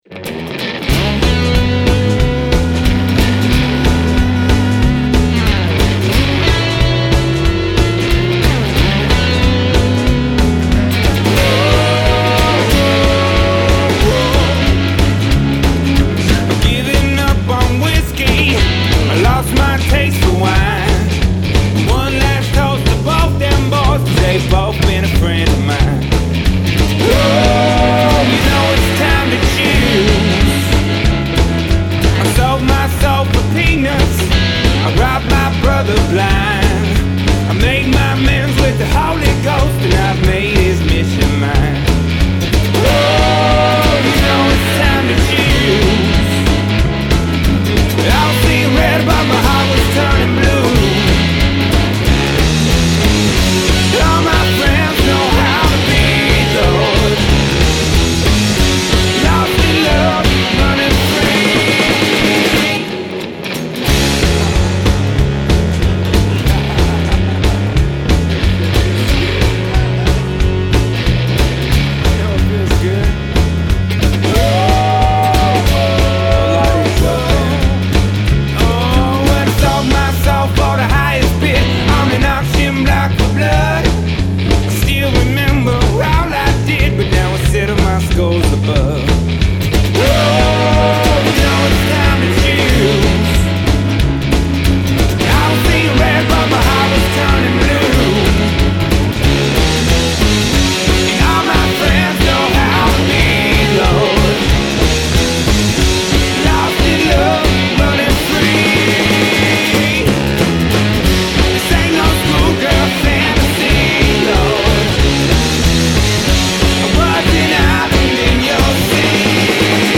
rowdy americana rock band